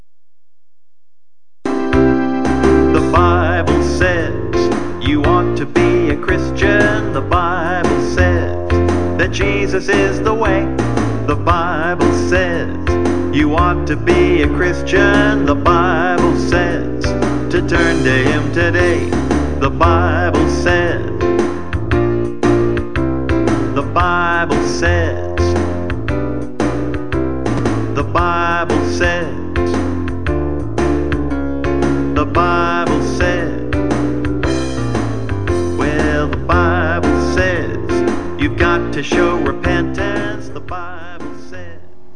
of Christ-centered music....